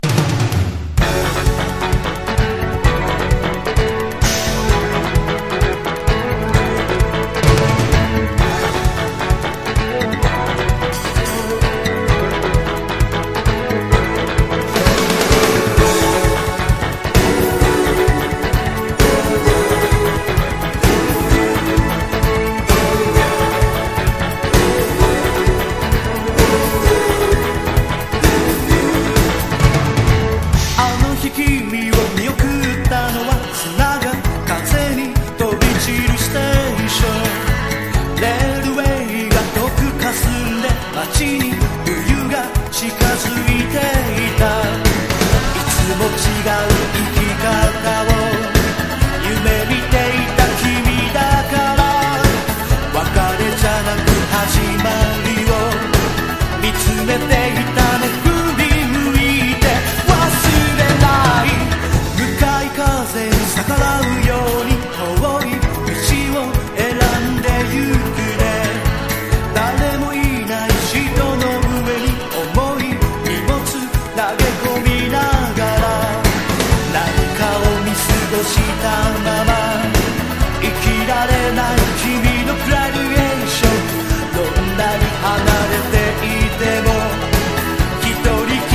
ポピュラー# TECHNO POP